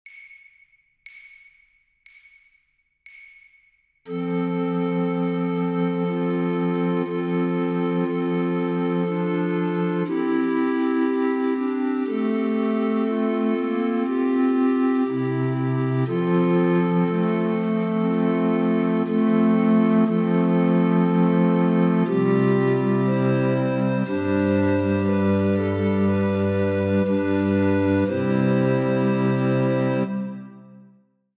Greatbass
Contrabass